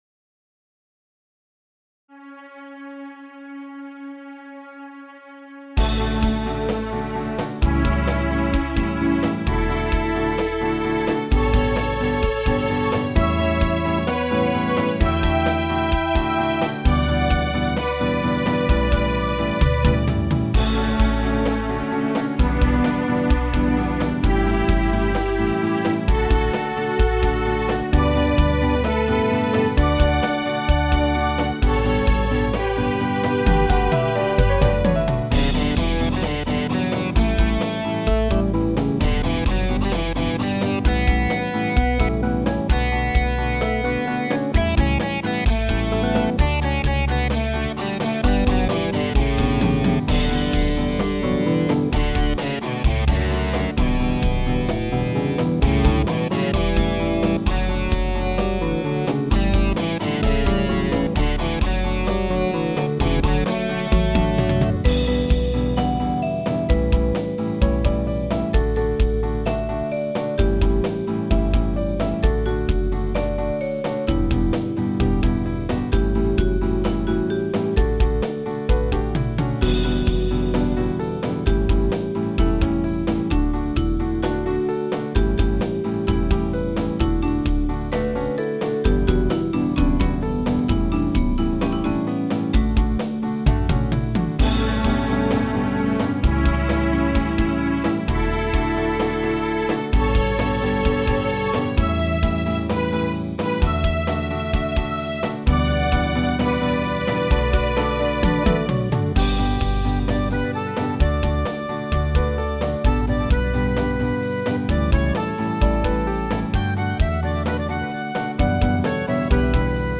オルガン・ギター・アコーディオン・ストリングス他